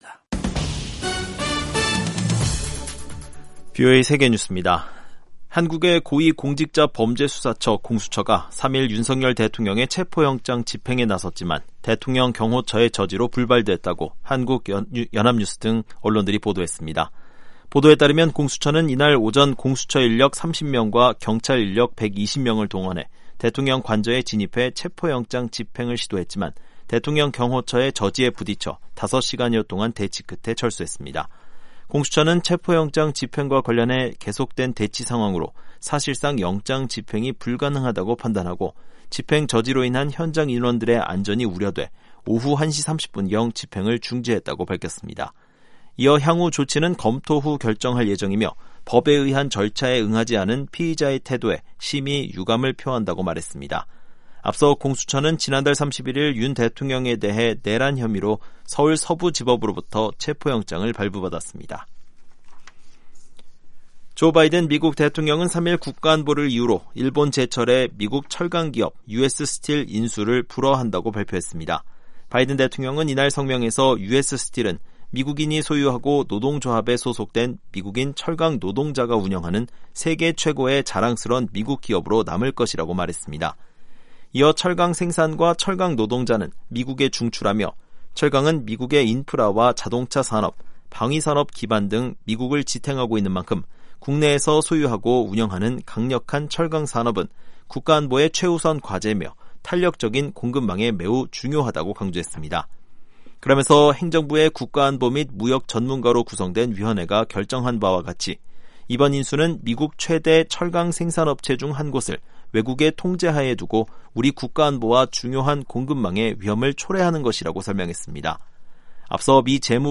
세계 뉴스와 함께 미국의 모든 것을 소개하는 '생방송 여기는 워싱턴입니다', 2025년 1월 4일 아침 방송입니다. 제119대 미국 연방 의회가 3일 개원합니다. 새해 첫날, 용의자 포함 15명의 목숨을 앗아간 미국 루이지애나주 뉴올리언스 차량 돌진 사건은 급진 이슬람 테러 조직 IS의 영감을 받은 단독범의 소행이라고 미 연방수사국(FBI)이 밝혔습니다. 유럽연합(EU)과 일본 간 ‘전략적 동반자 협정’이 1일 발효됐습니다.